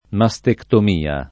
mastectomia [ ma S tektom & a ]